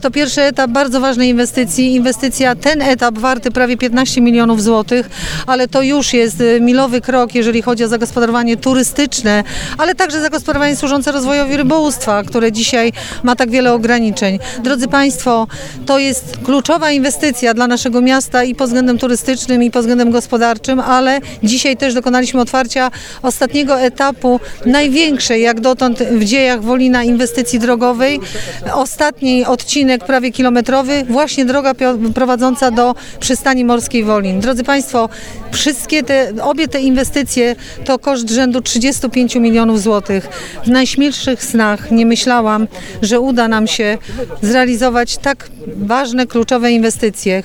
Rodzinny festyn zorganizowano w sobotnie popołudnie w Wolinie.
Nie byłoby to możliwe gdyby nie przyjaciel Gminy Wolin wiceminister Marek Gróbarczyk – mówi Ewa Grzybowska, burmistrz Wolina.